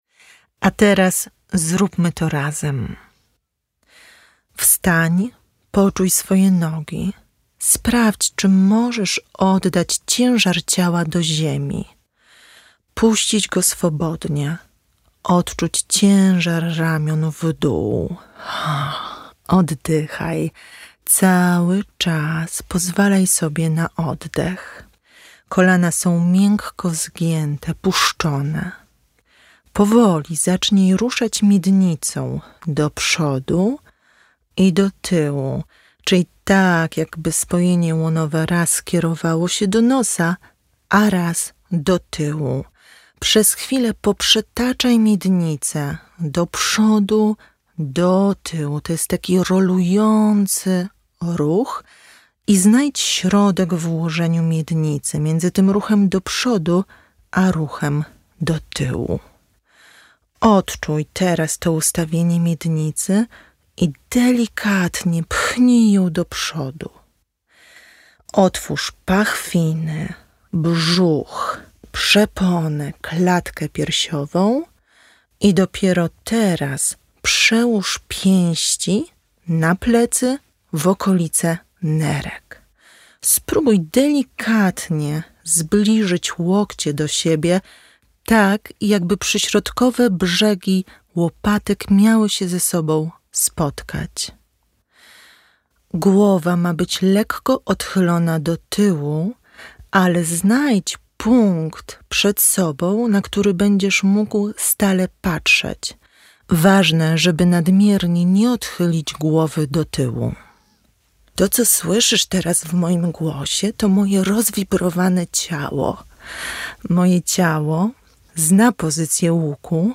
Przed nami ŁUK i pierwszy, aż tak praktyczny fragment audiobooka. W nagraniu jest już sama część prowadzona, ale poniższy rysunek pokazuje, jak wyjściowo się ustawić.